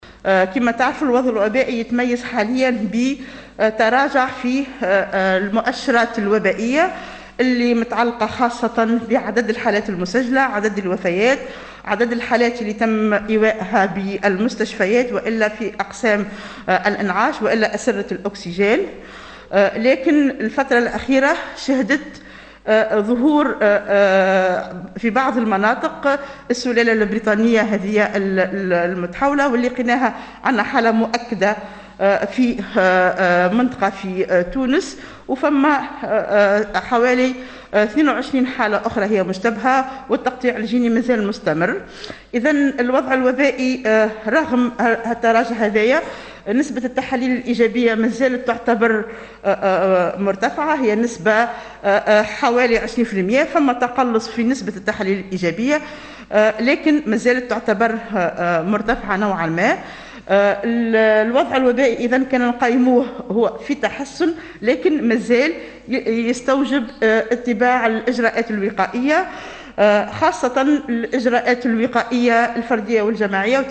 خلال الندوة الدورية المتعلقة بالإجراءات المتخذة من قبل الهيئة الوطنية لمجابهة فيروس كورونا